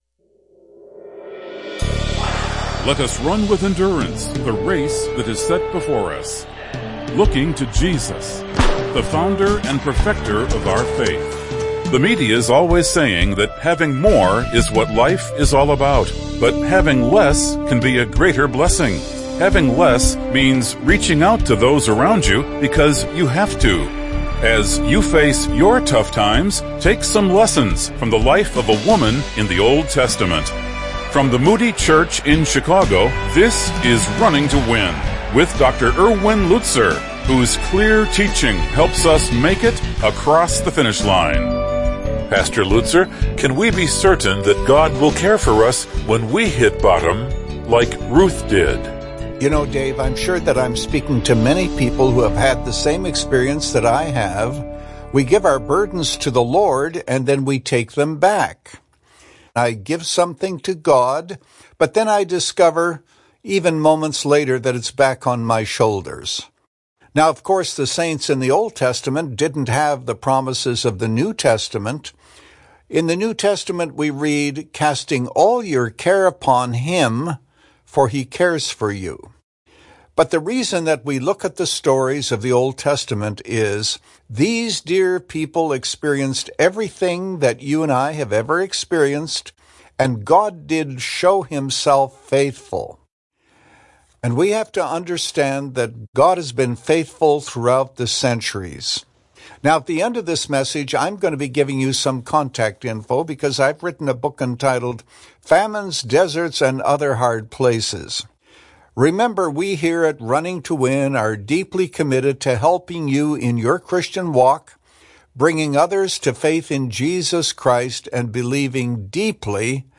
The book of Ruth reveals how God works through relationships, bringing redemption through unlikely people. In this message, Pastor Lutzer considers two lessons for us about our community and our witness.